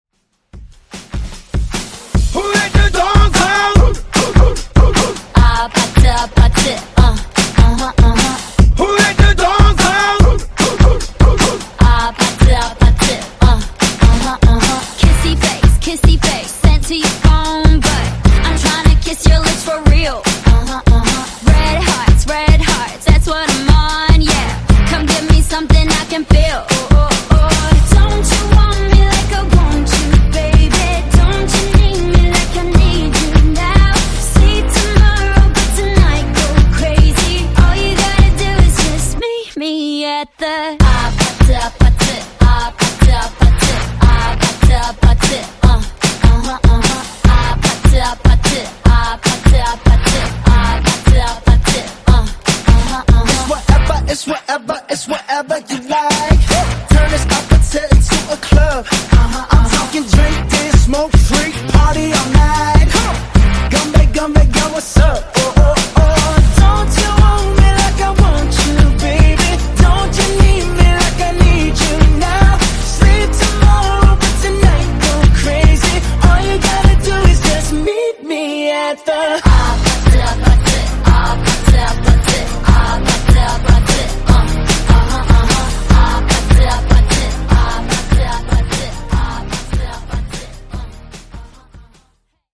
Genres: RE-DRUM , REGGAETON Version: Clean BPM: 98 Time